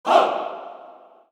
Waka- HOO 3.WAV